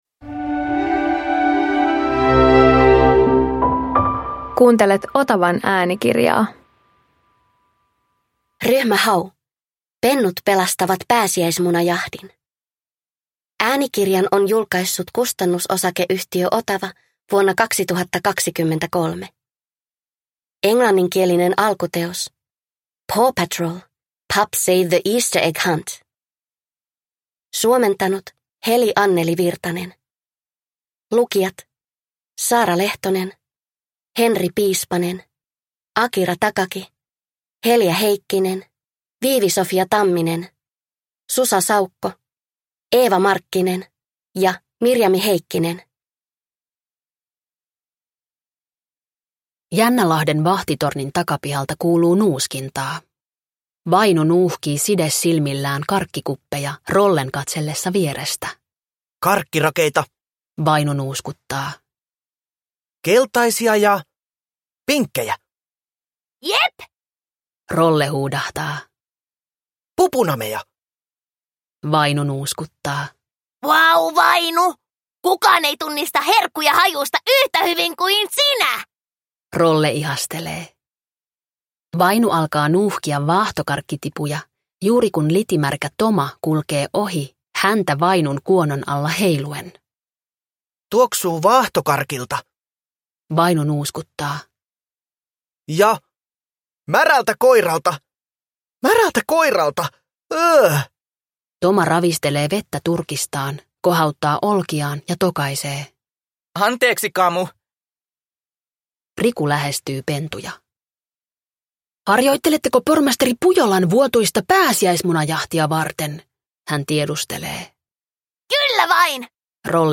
Ryhmä Hau - Pennut pelastavat pääsiäismunajahdin – Ljudbok